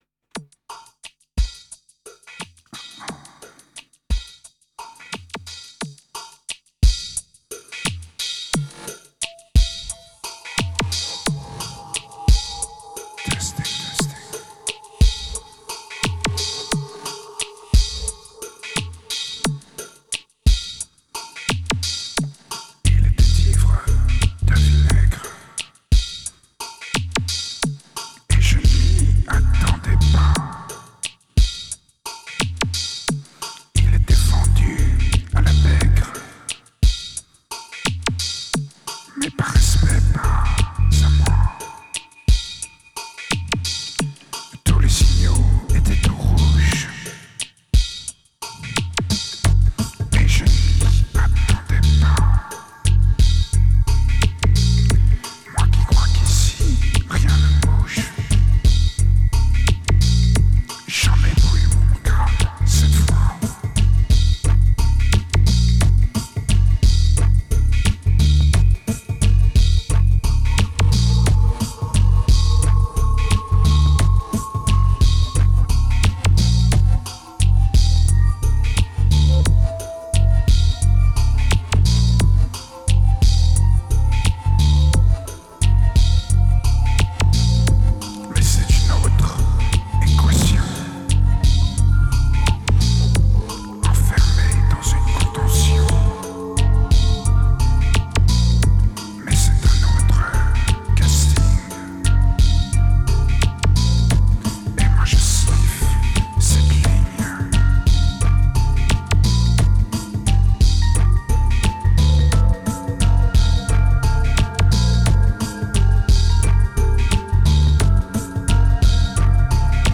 It's raw, it's dirty and it's lame, but are you up to it ?